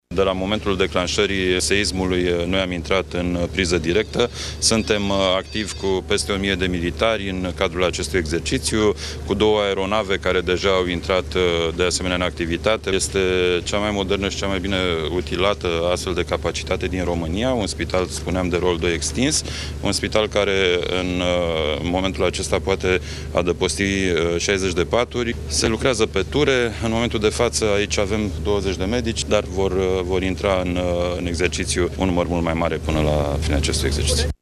Ministrul Apărării: